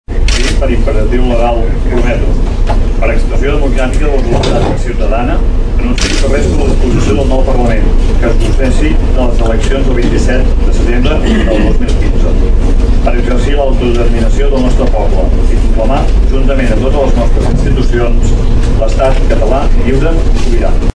En aquest cas, Tordera té representació directa al plenari amb Xavier Pla, regidor d’ERC al nostre municipi. Pla jurava així el seu càrrec.
jurament-xavi-pla.mp3